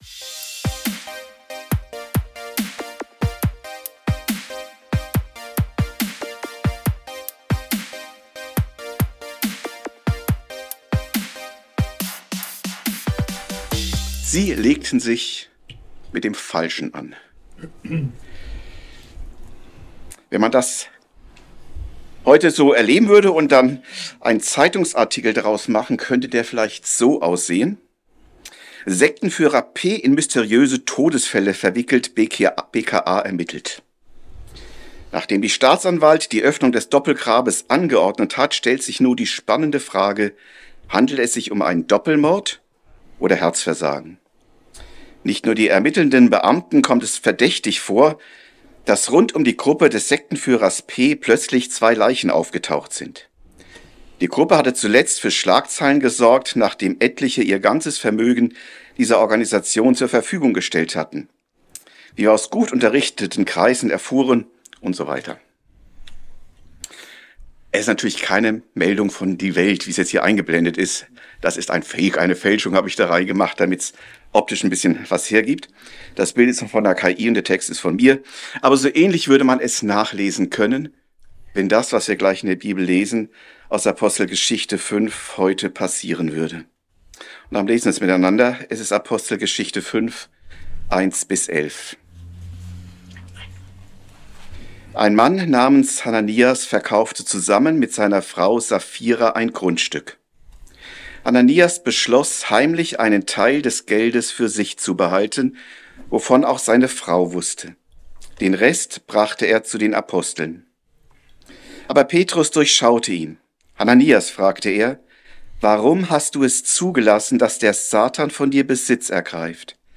A04 Sie legten sich mit dem Falschen an ~ Predigten u. Andachten (Live und Studioaufnahmen ERF) Podcast